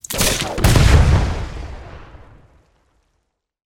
На этой странице представлены звуки мин – от глухих подземных взрывов до резких срабатываний нажимных механизмов.
Грохот разорвавшейся мины